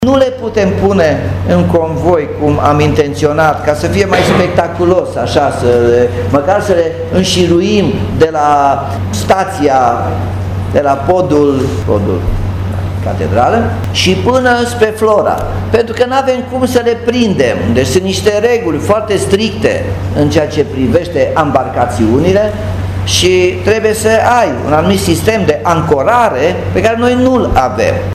Primarul a spus că la ora actuală patru ambarcațiuni sunt în zona centrală și trei în zona Iosefin. Edilul a mai spus că și în perioada următoare se se vor face curse gratuite pentru testarea ambarcațiunilor, urmând ca abia după aceea să se facă un program regulat de transport.